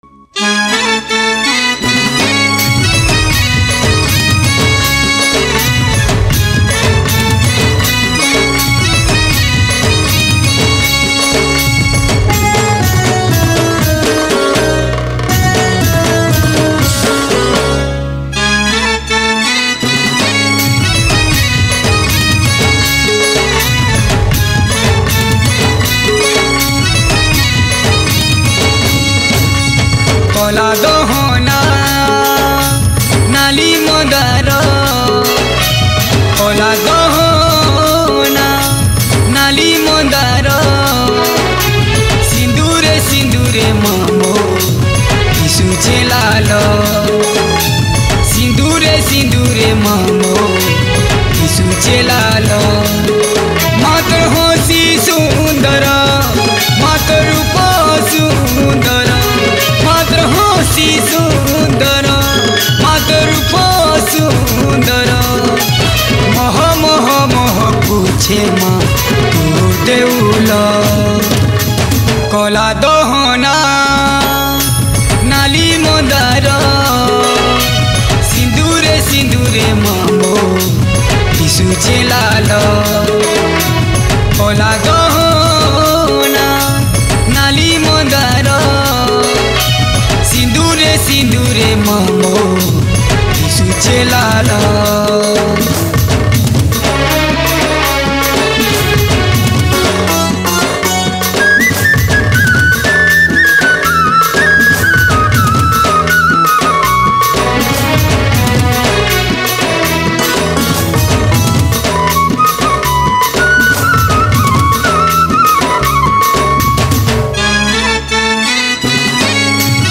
Category: Sambalpuri Bhajan Single Songs